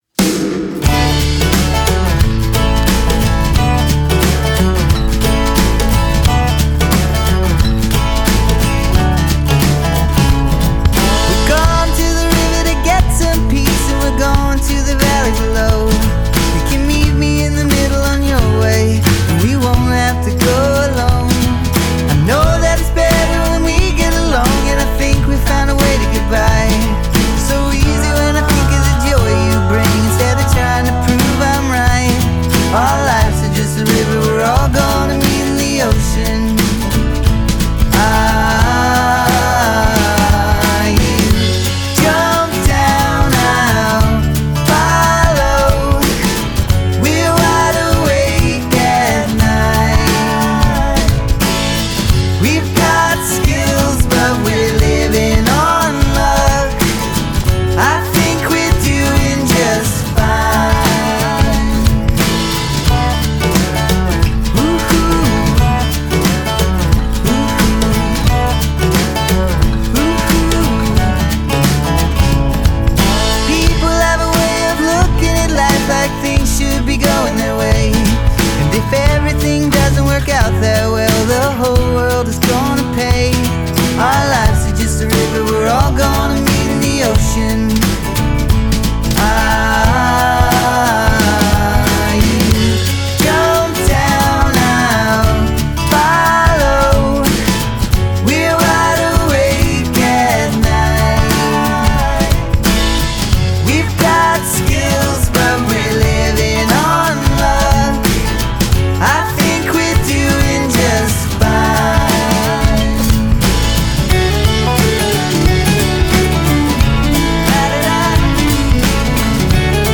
opens things up with a nice swinging melody